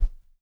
WHOOSH_Deep_mono.wav